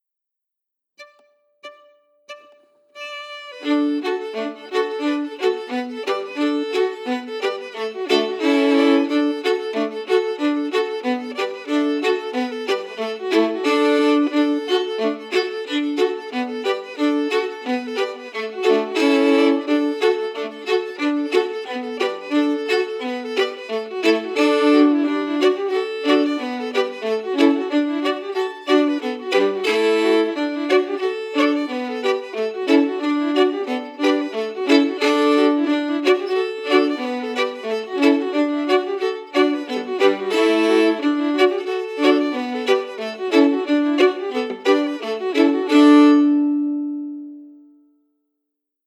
Key: D*
Form: Reel
Harmony emphasis
Region: Acadia, Maritime Canada, PEI? New Brunswick?